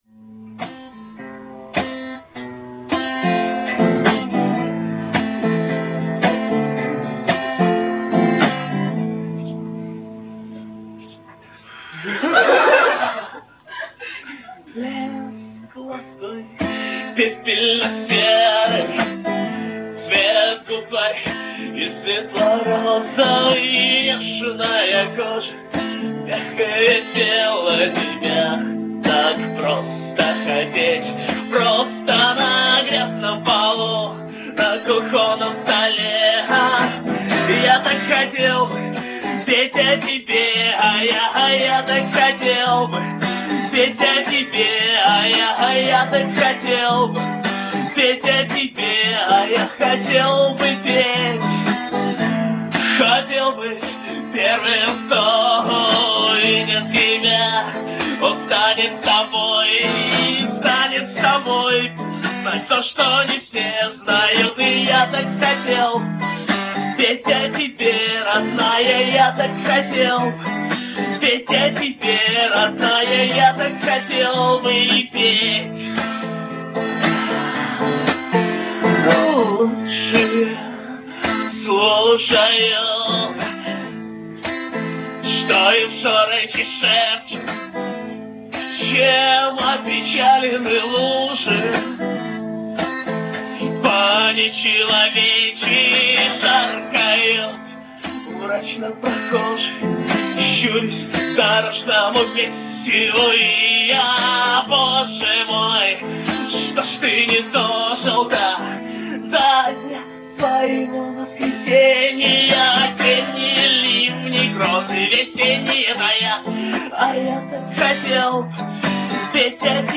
Квартирник 26 октября 1999.